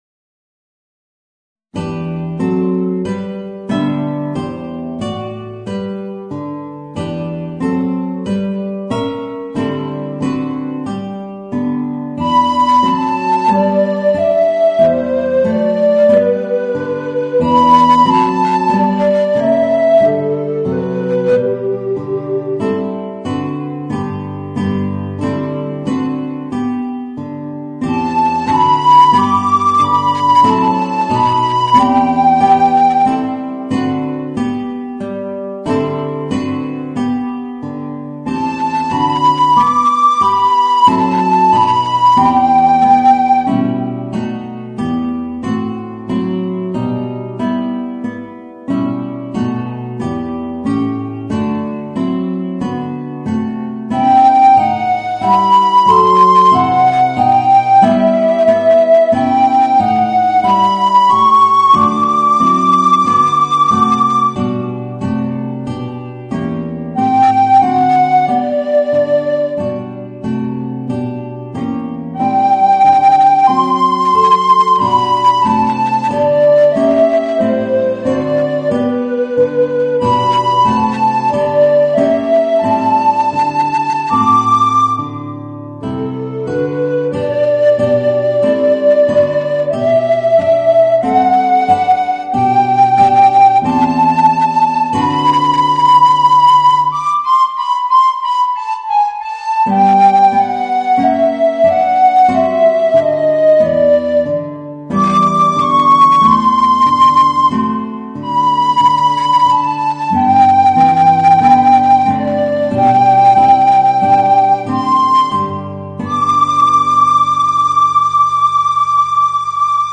Voicing: Alto Recorder and Guitar